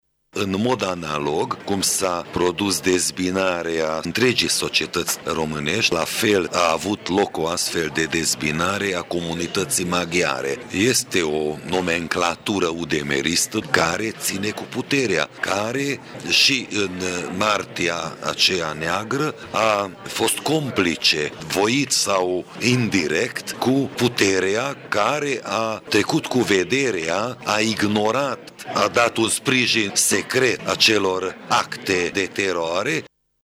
El a spus că actualii lideri UDMR au fost complici la evenimentele sângeroase din martie 1990 de la Tîrgu-Mureș: